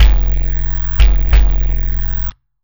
Kick Particle 05.wav